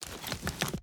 Bow Take Out 1.ogg